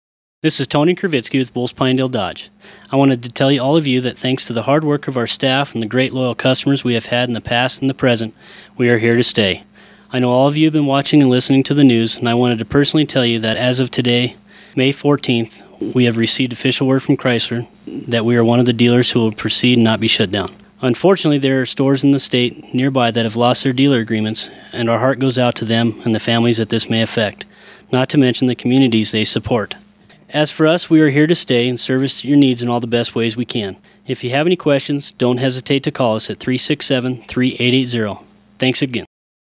courtesy KPIN 101.1 FM Radio